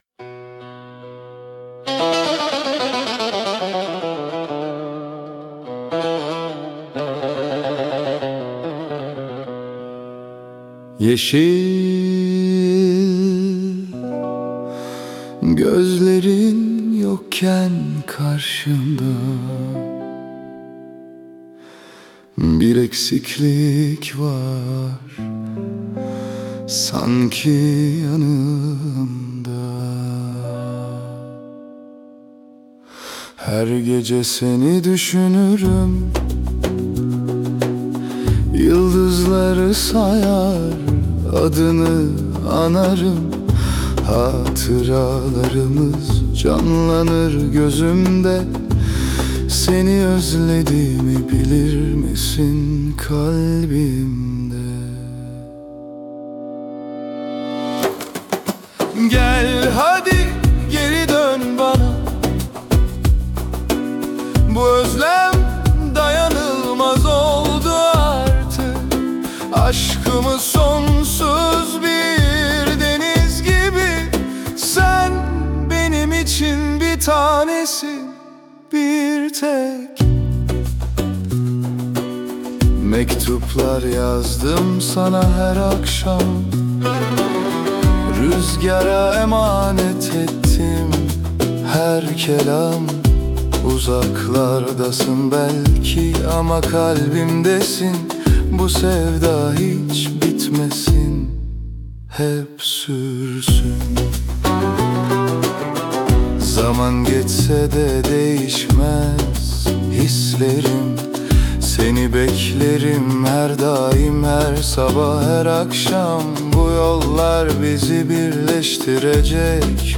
AI ile üretilen 58+ özgün müziği keşfedin
🎤 Vokalli 02.12.2025